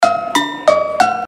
без слов
короткие
звонкие
Ещё одно «металлическое» оповещение в копилку